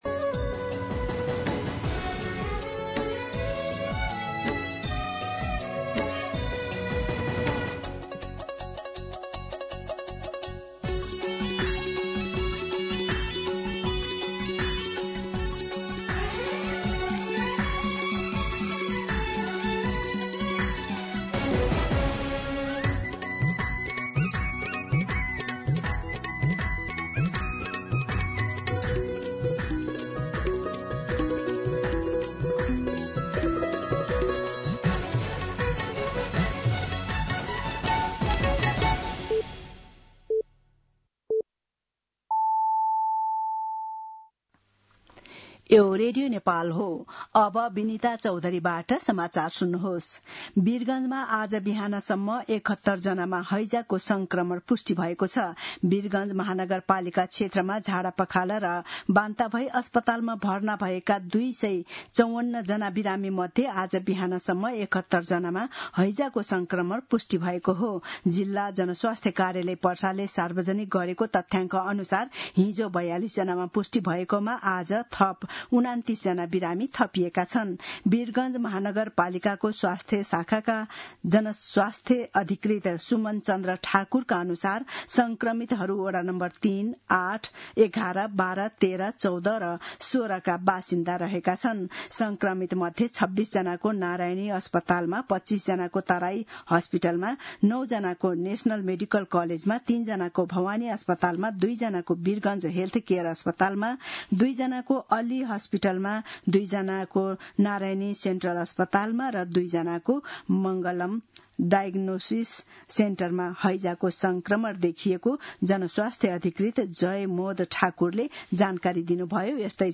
An online outlet of Nepal's national radio broadcaster
मध्यान्ह १२ बजेको नेपाली समाचार : ९ भदौ , २०८२